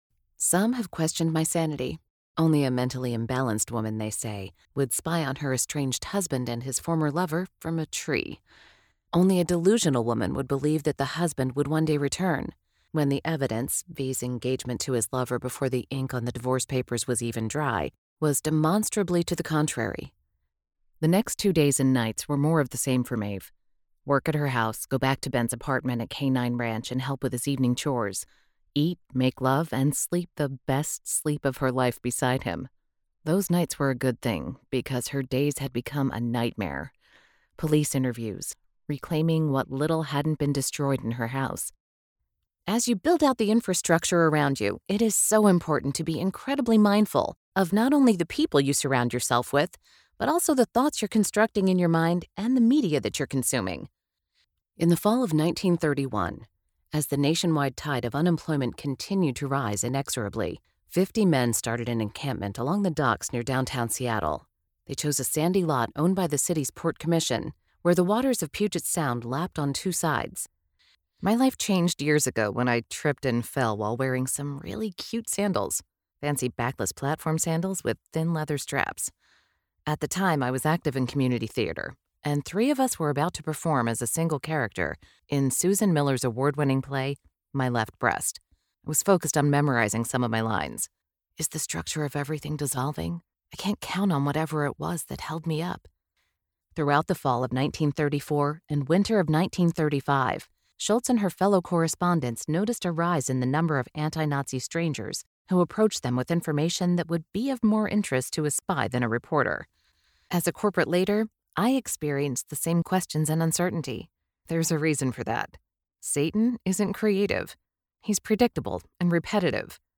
Audio Book Voice Over Narrators
Adult (30-50) | Yng Adult (18-29)